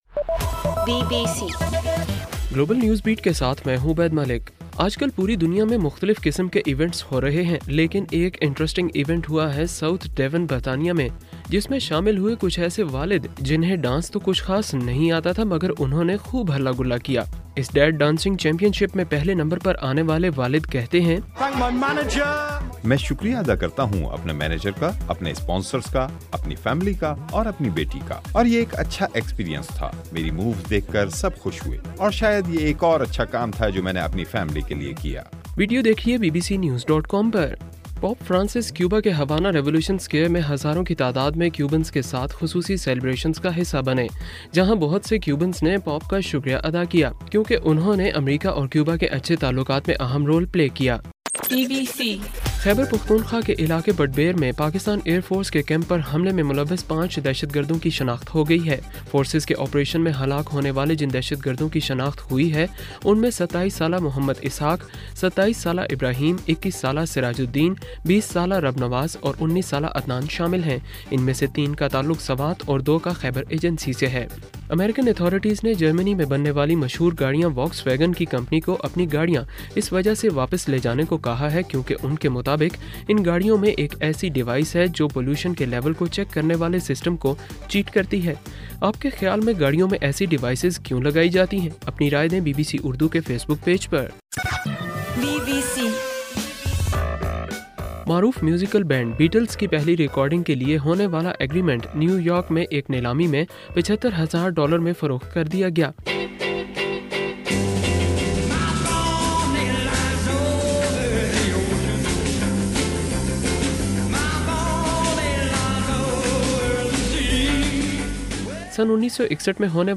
ستمبر 20: رات 9 بجے کا گلوبل نیوز بیٹ بُلیٹن